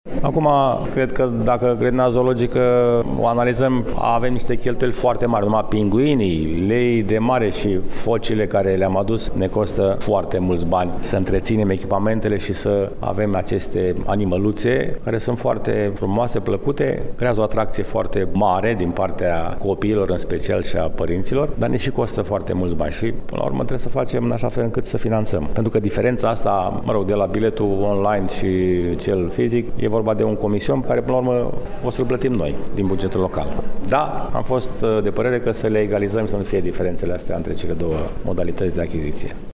Primarul municipiului Brașov, George Scripcaru: